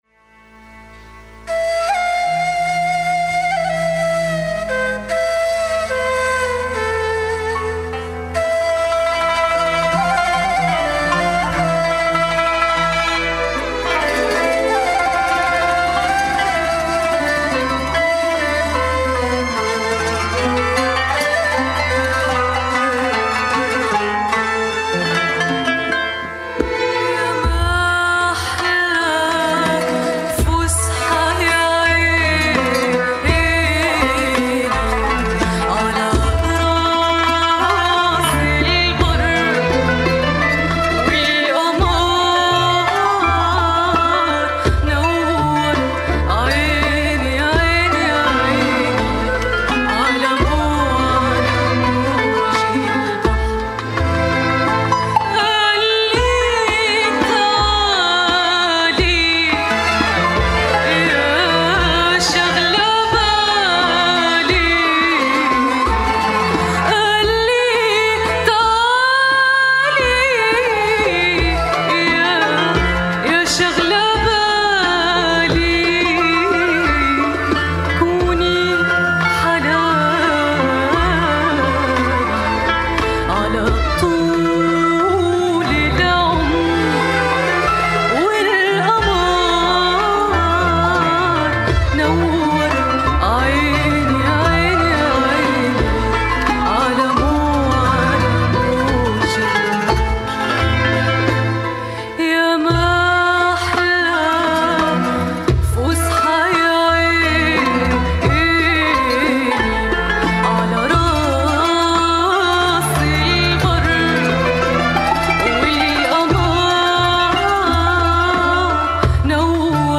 Columna de análisis internacional